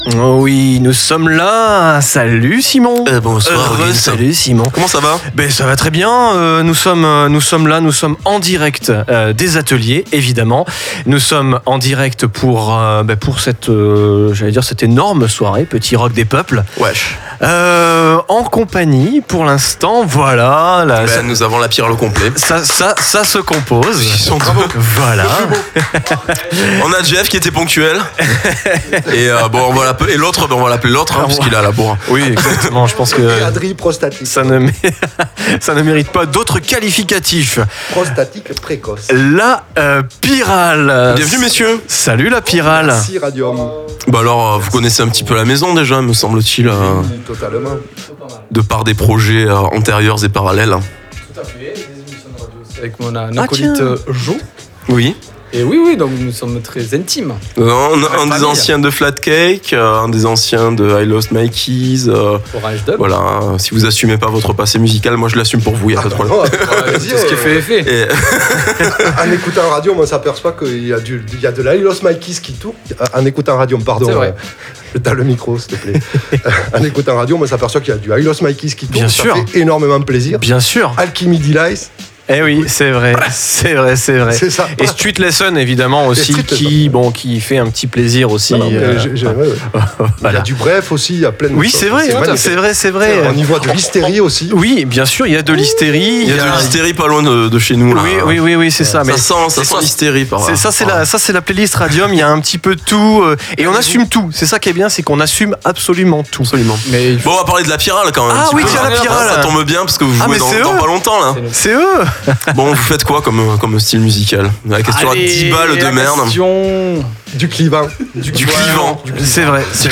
Duo électro-rock sombre, francophonie rebelle et pulsée.